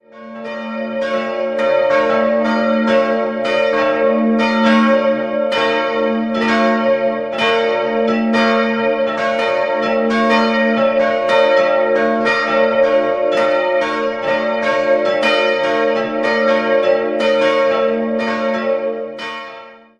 3-stimmiges Geläute: ais'-cis''-e''